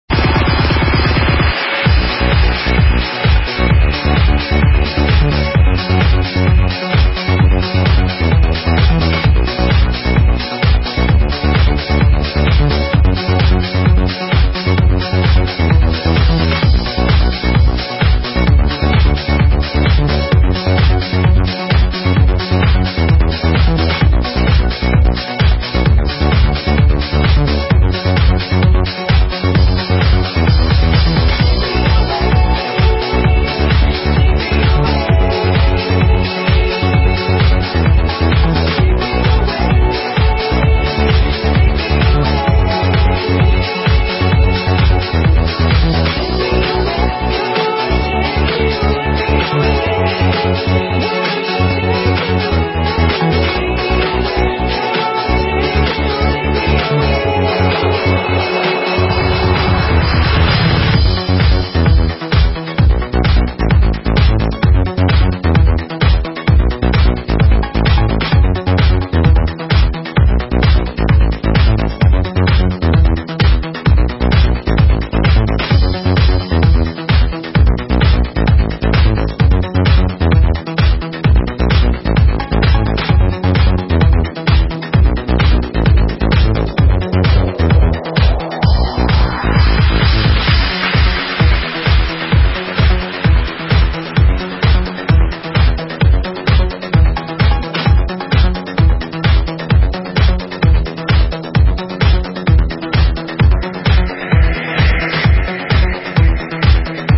Друзья это (Electro house)